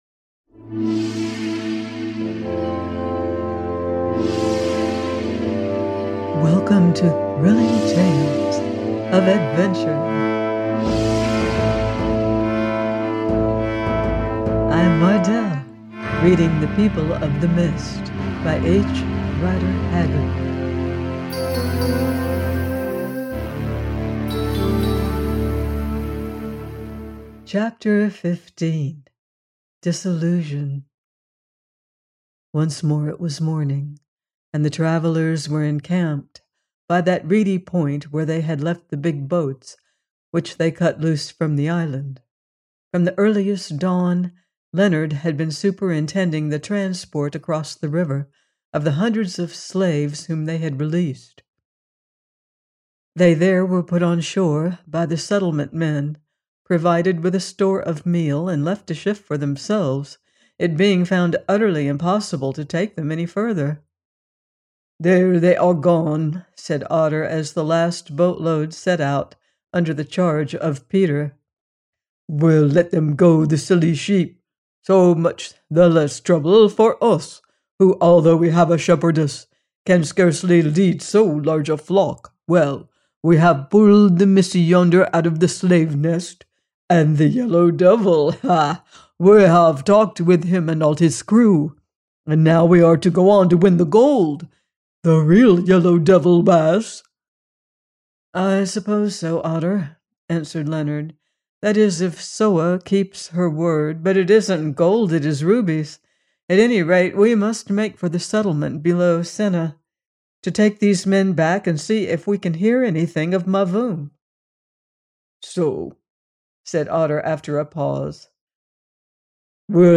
The People Of The Mist – 15: by H. Rider Haggard - audiobook